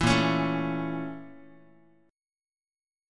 BbmM7b5 chord